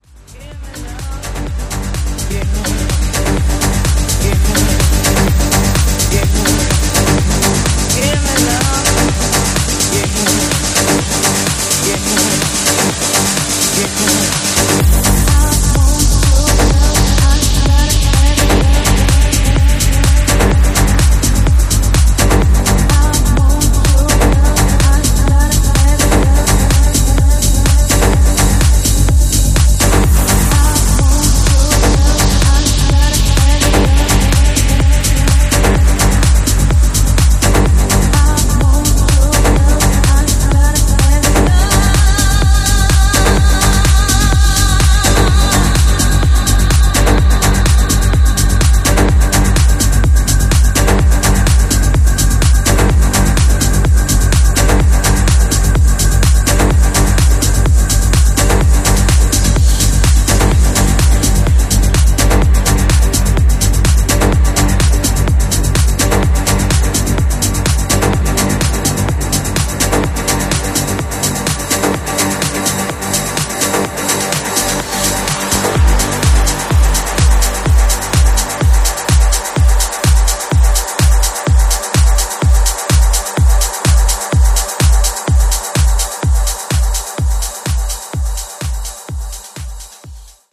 progressive titan